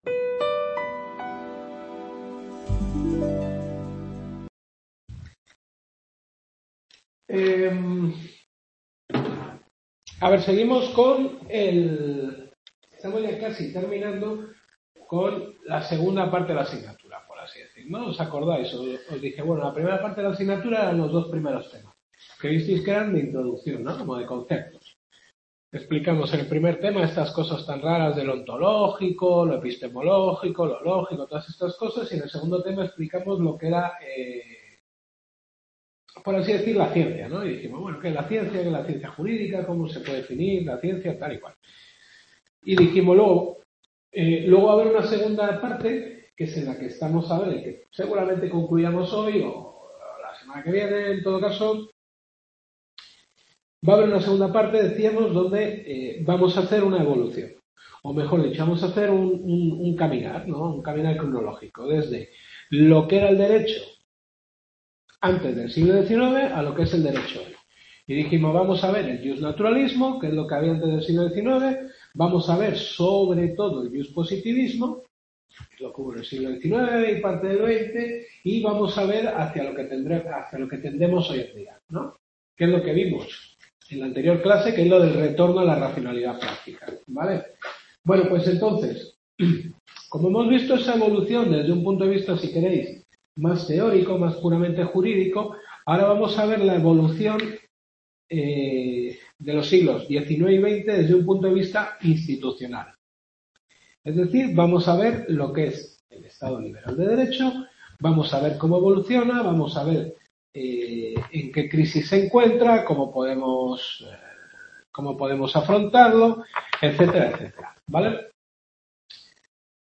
Filosofía del Derecho. Quinta Clase.